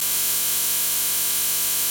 На этой странице собрана коллекция звуков цифровых глюков, сбоев и помех.
Звук заклинивания программы для монтажа тррр